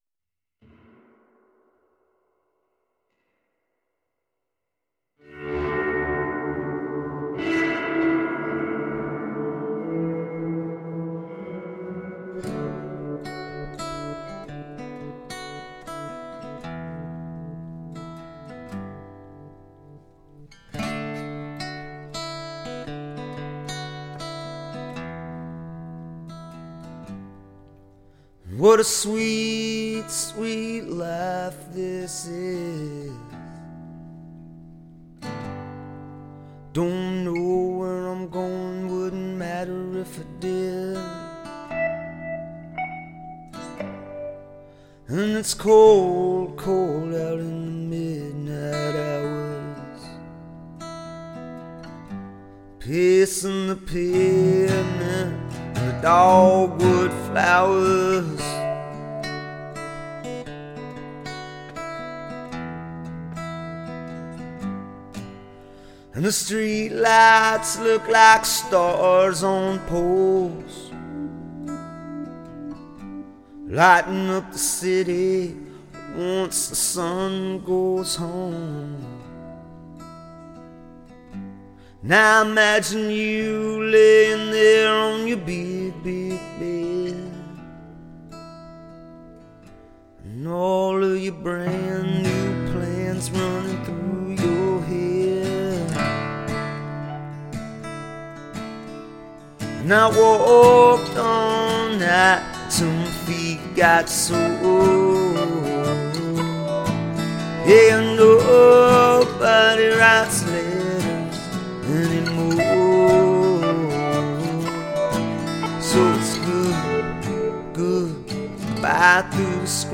singer-songwriter
a beautiful poignant stripped-down piece